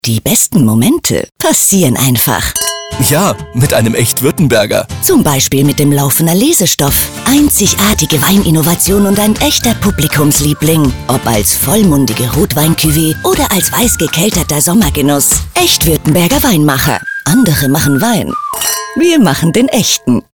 sehr variabel
Mittel minus (25-45)
Commercial (Werbung)